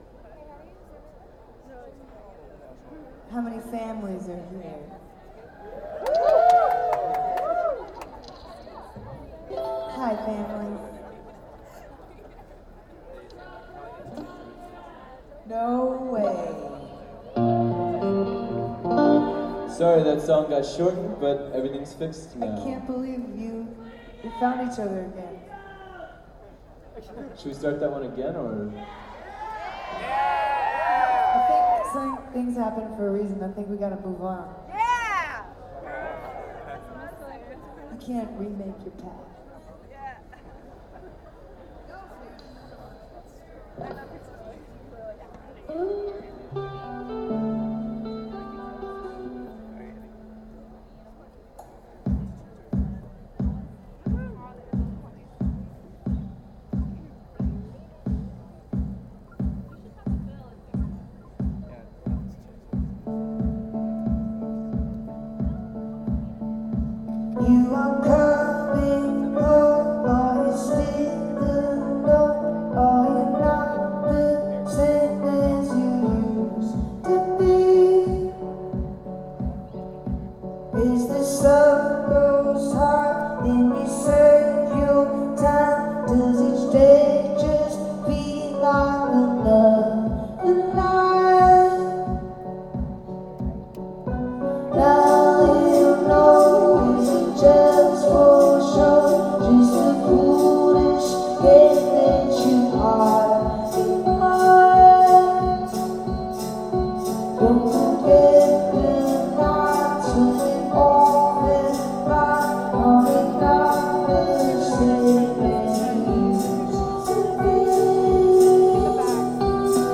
Prospect Park 07-27-10